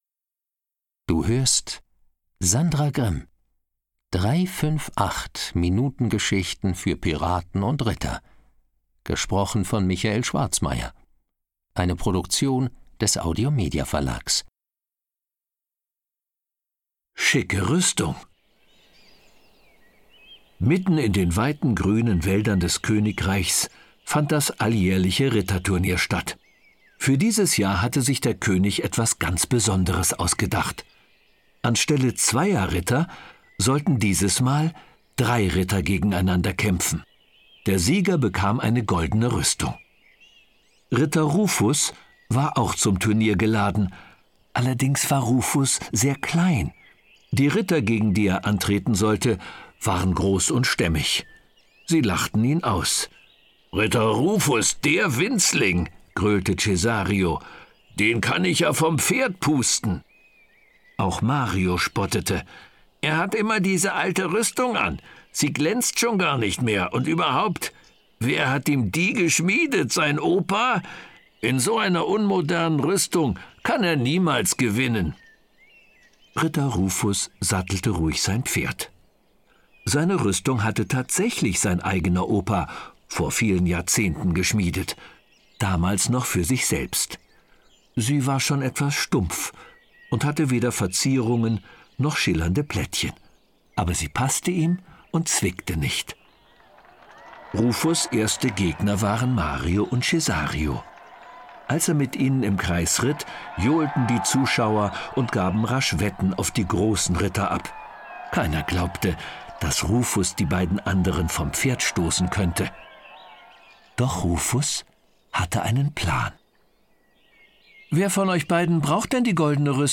Die abwechslungsreiche Gestaltung der Figuren macht seine Lesungen zu einem großen Vergnügen für die ganze Familie.